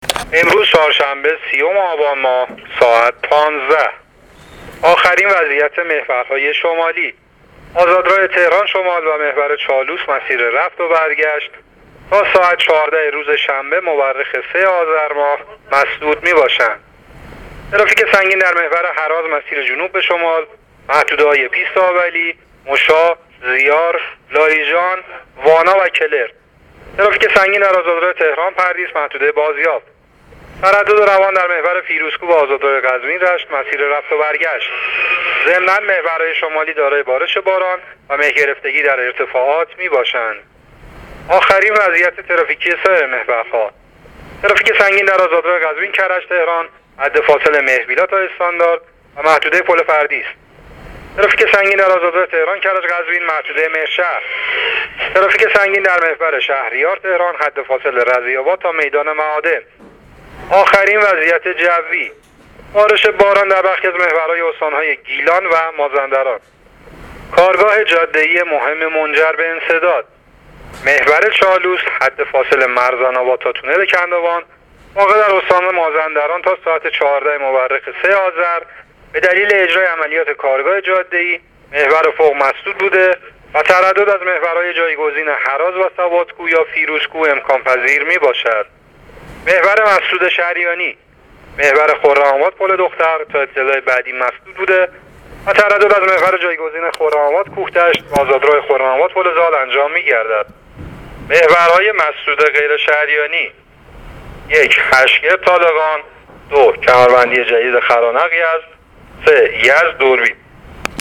گزارش رادیو اینترنتی از آخرین وضعیت ترافیکی جاده‌ها تا ساعت ۱۵ سی‌ام آبان؛